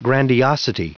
Prononciation du mot grandiosity en anglais (fichier audio)
Prononciation du mot : grandiosity